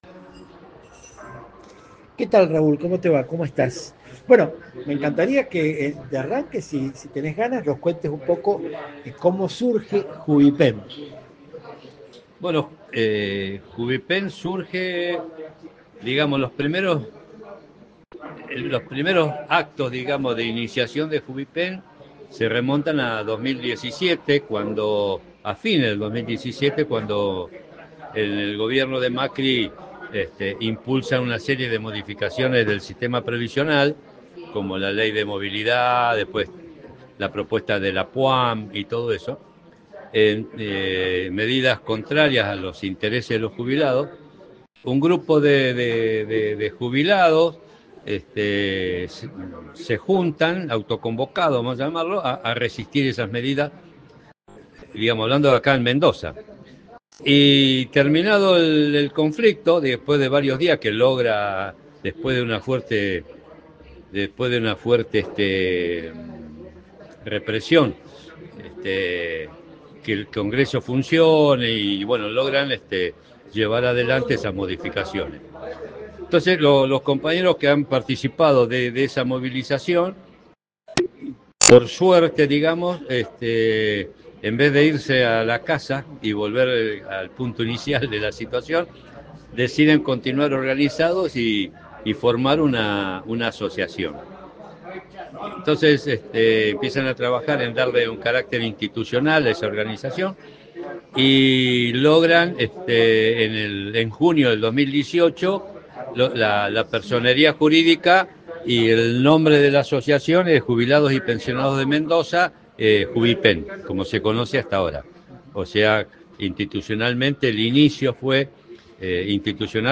Entrevistas militantes